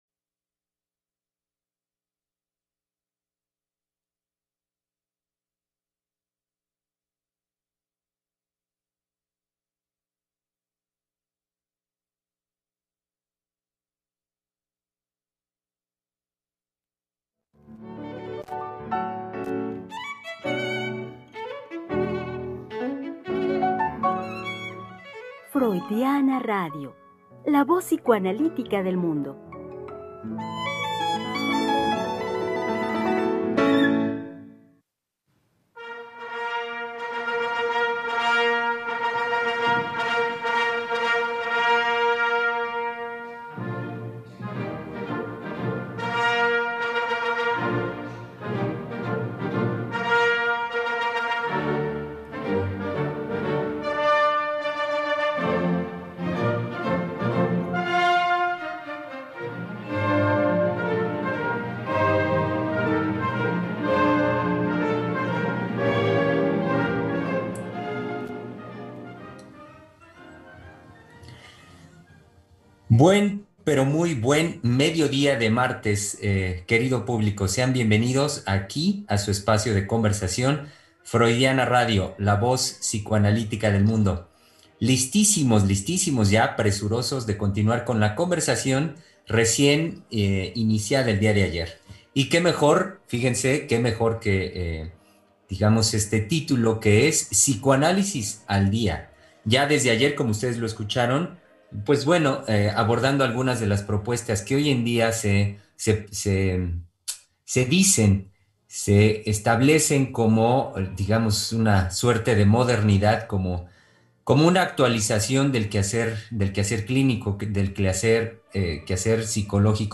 Conversación psicoanalítica